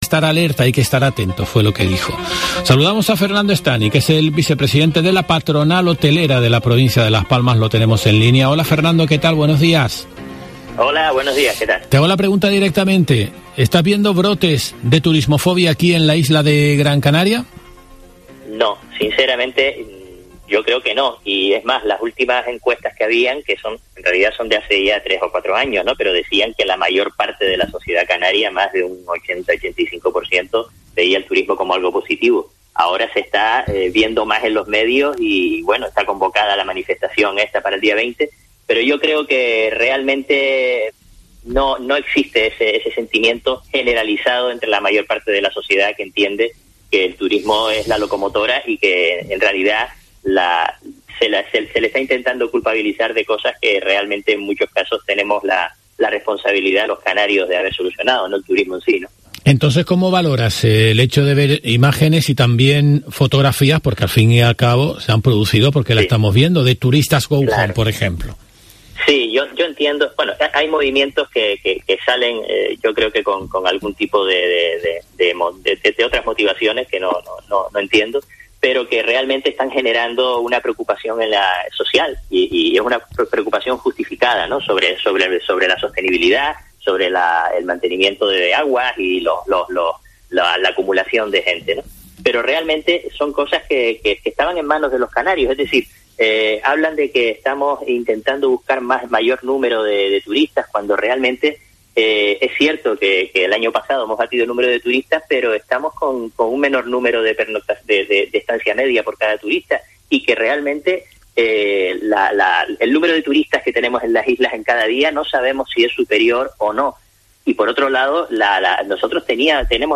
No ocurre lo mismo en Gran Canaria, tal y como ha dicho hoy en Herrera en COPE Gran Canaria.